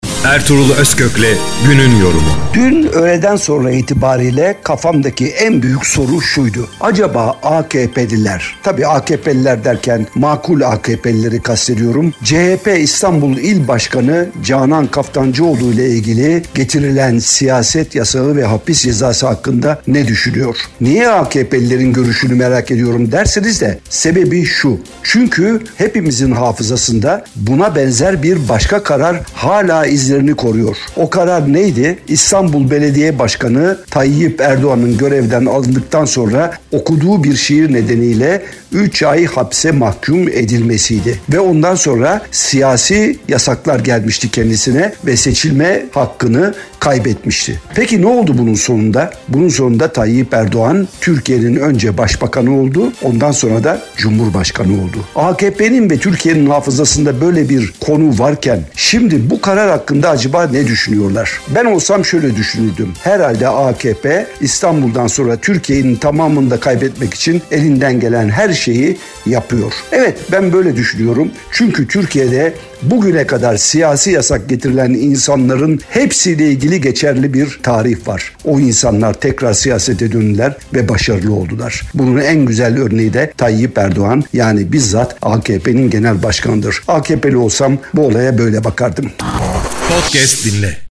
TALK SHOW
ERTUGRUL-OZKOK_13-MAYIS-YORUM-JINGLELI.mp3